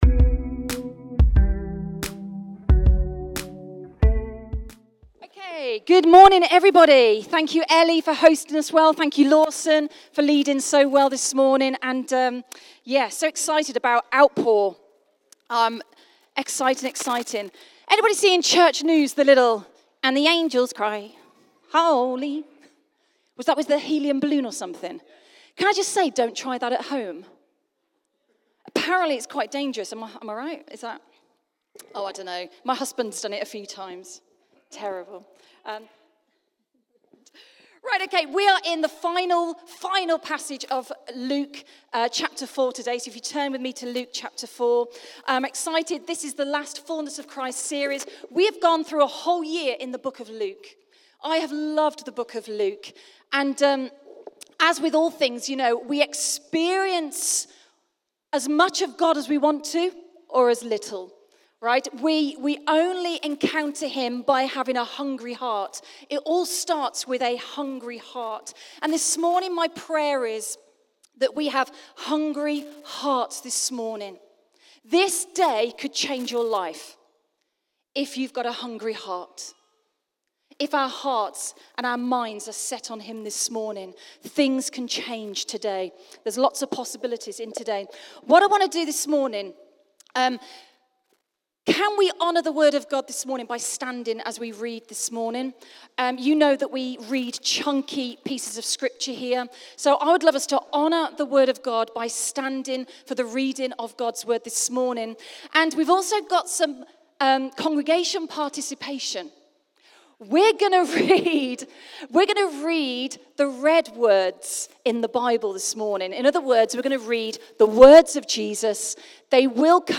Sunday Messages | The Fullness of Christ